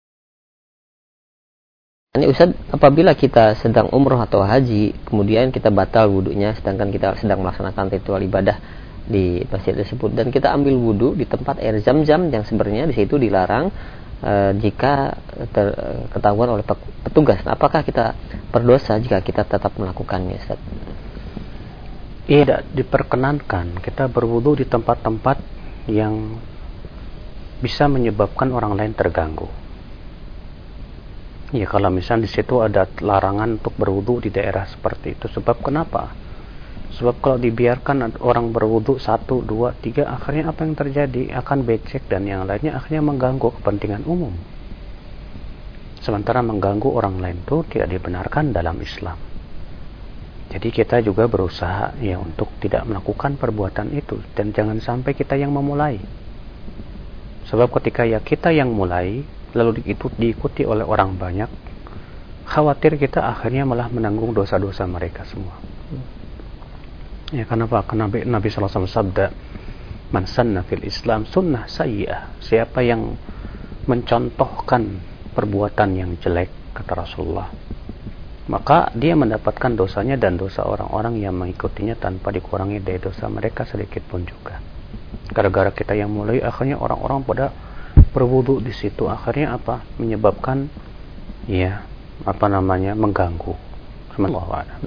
Kajian Audio